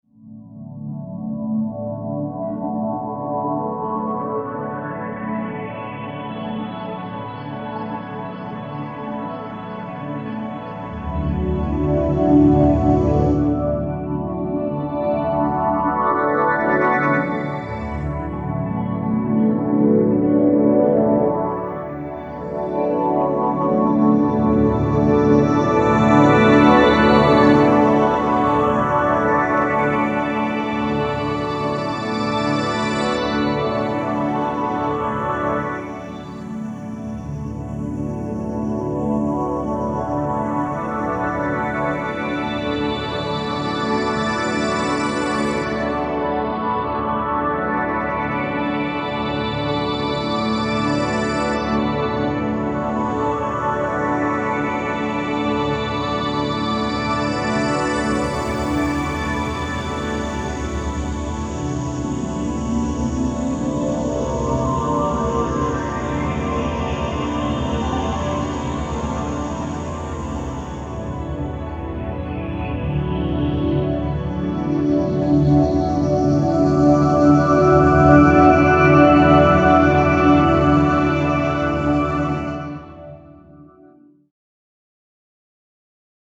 - Long Spheric Ambient Pads -
19 Glimmerpad